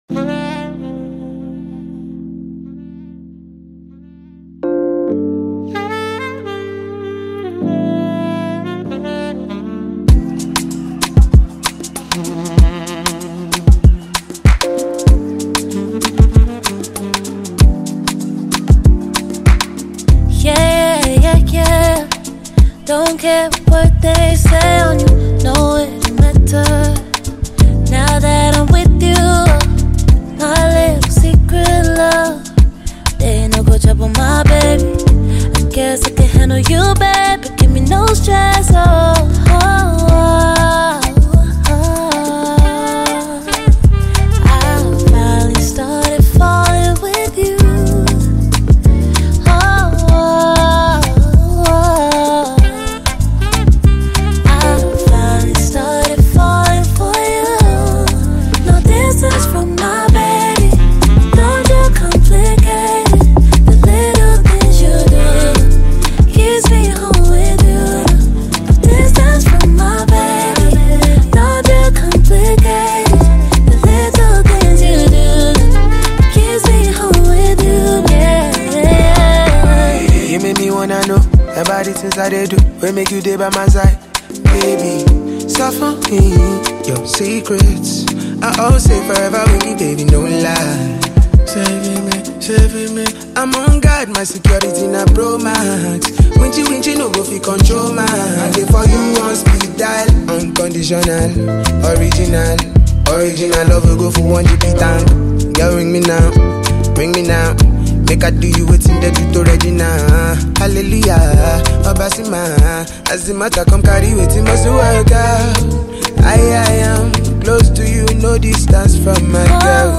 featuring the soulful vocals of UK singer
all wrapped in a rich, melodic sound.